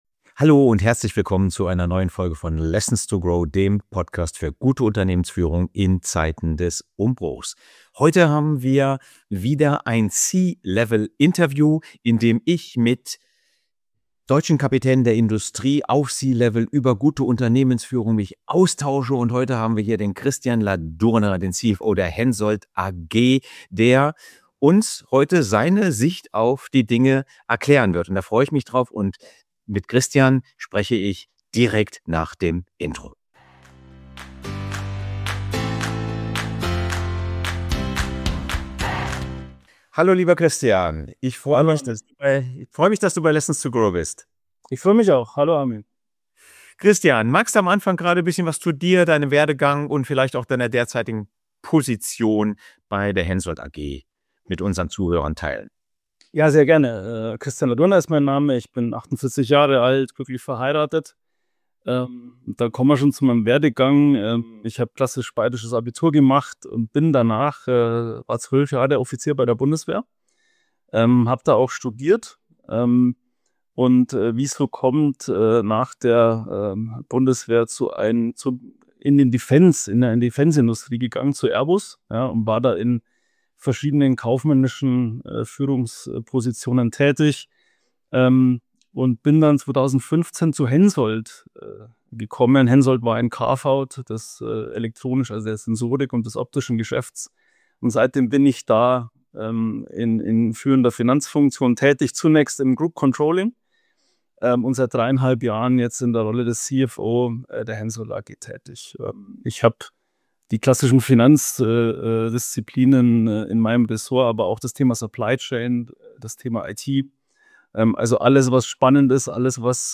Tauche ein in ein Gespräch über Integrität, Feedbackkultur und die harten Fakten anorganischen Wachstums, das Dich und Deine Führungskompetenz nachhaltig inspirieren wird. Wenn Du wissen willst, wie Du Dein Unternehmen sicher durch stürmische Gewässer steuerst und dabei Deine Prinzipien nicht verkaufst, ist dieses Interview ein absolutes Muss für Dich.